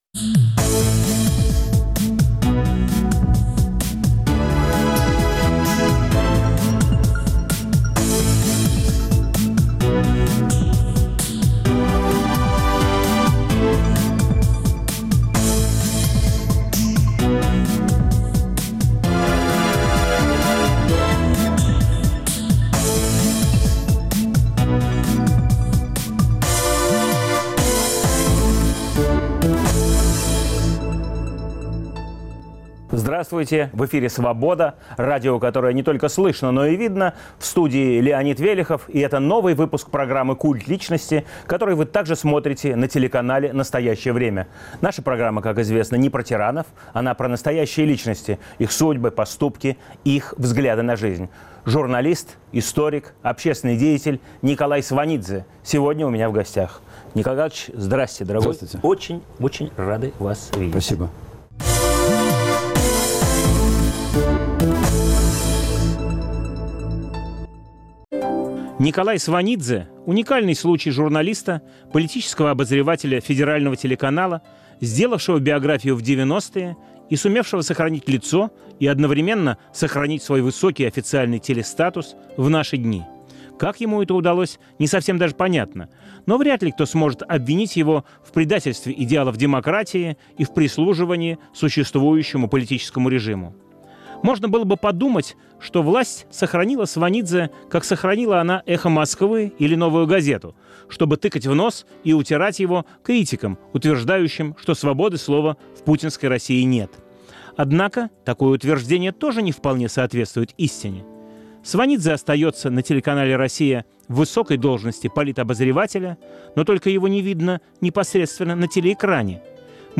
В студии нового выпуска "Культа личности" журналист Николай Сванидзе. Автор и ведущий - Леонид Велехов.